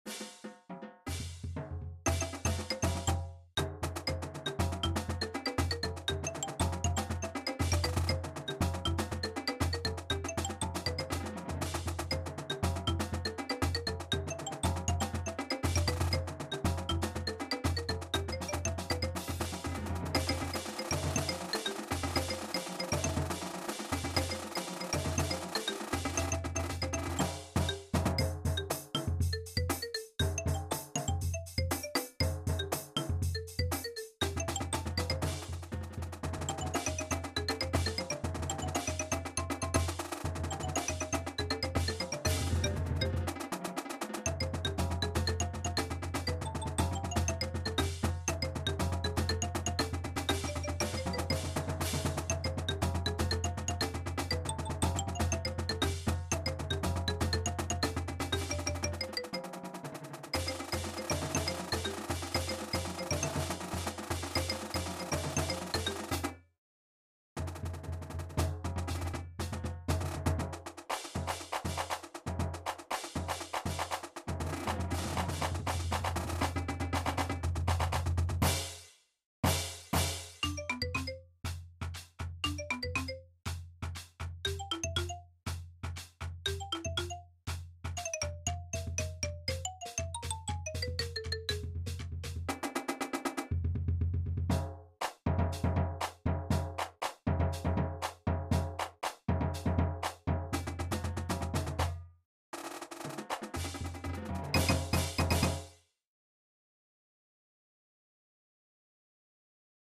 Mallet-Steelband Muziek
Mallets Snare drum Quint toms Bass drums Cymbals